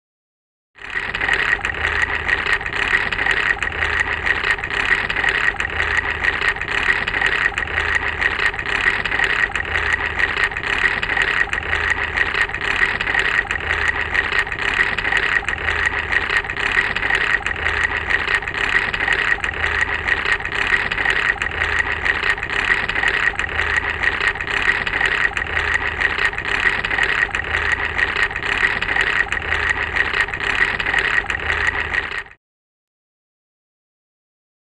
Loom; Hand Loom Running. Fast Ratchet Noise.